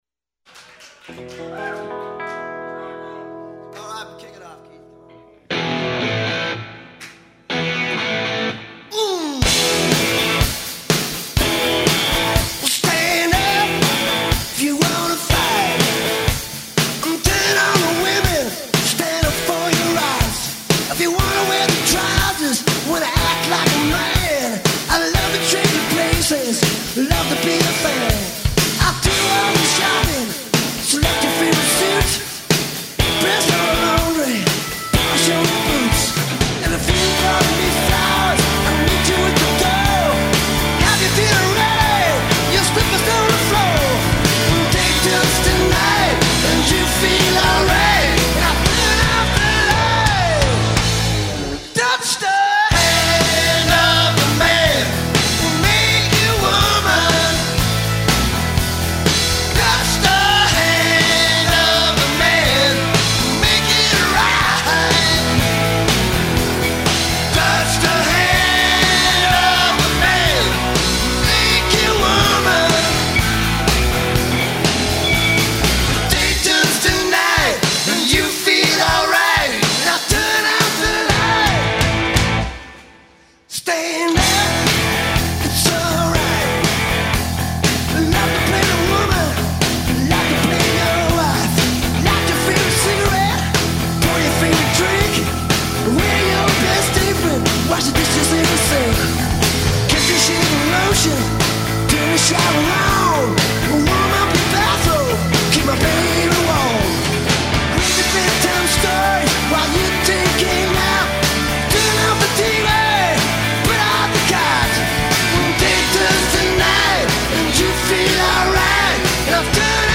absolutely live.